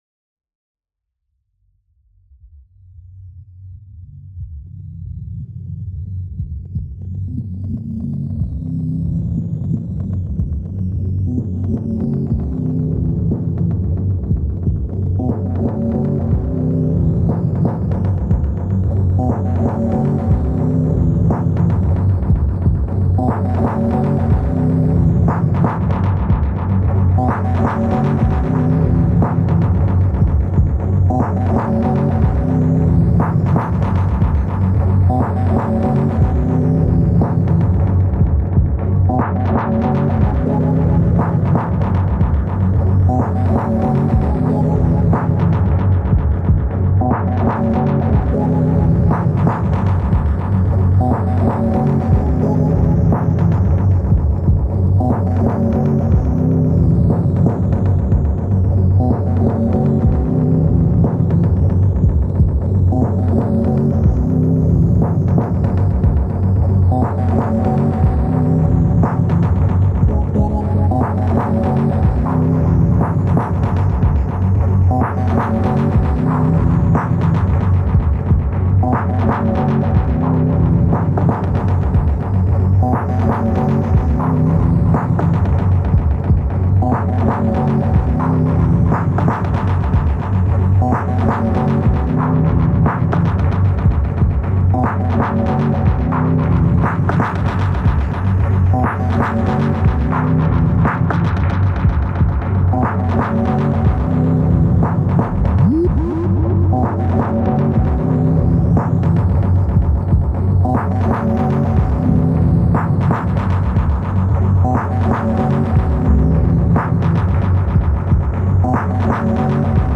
Started with chopping in Serato Sample, doing some drums on the SP-16 then throwing the whole lot on the 404mk2 and got messing.
Live takes recording into Ableton triggering one shots and effects so it’s sloppy and not mixed or anything, Fun experimenting though.
Those sweeps that came at the 3:50ish mark were lovely to listen to on the first track, and has the makings of a heavy movie/show scene backing track!